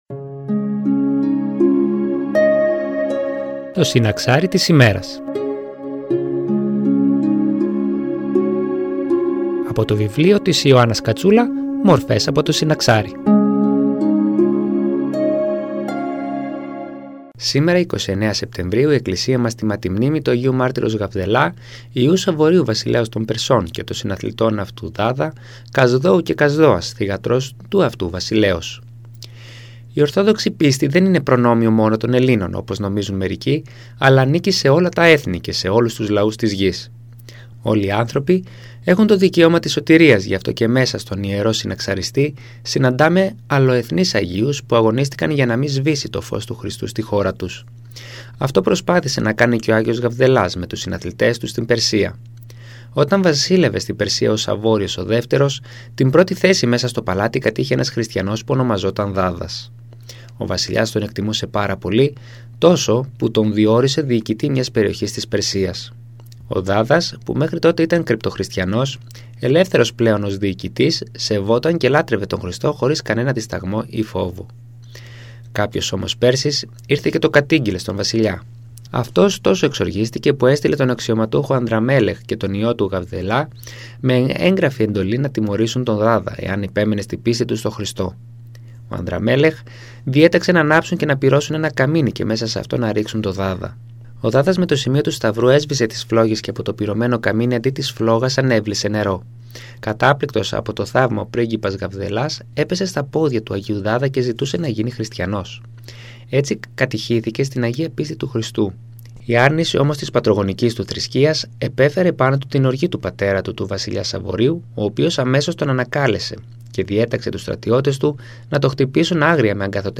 Μια ένθετη εκπομπή που μεταδίδονται καθημερινά οι βίοι των αγίων που εορτάζονται από την Εκκλησία μας, από το βιβλίο της Ιωάννας Κατσούλα «Μορφές από το συναξάρι».
Εκκλησιαστική εκπομπή